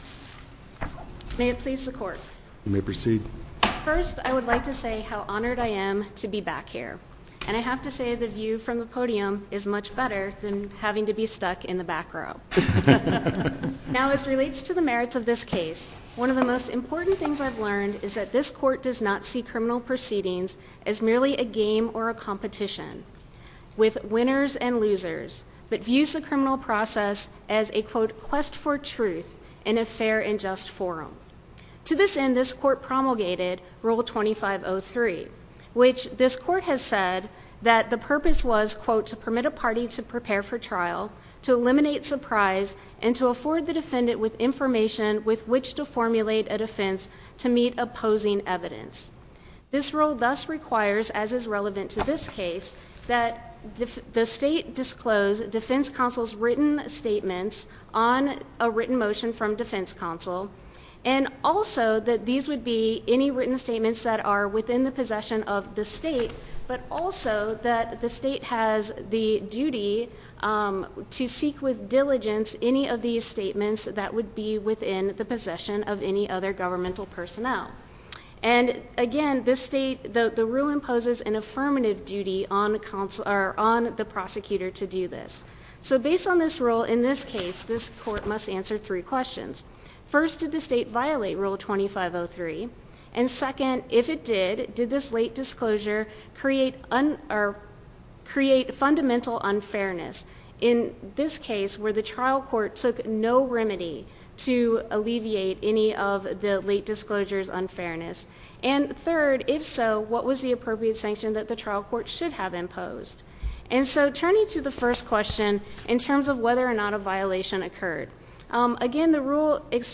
link to MP3 audio file of oral arguments in SC97229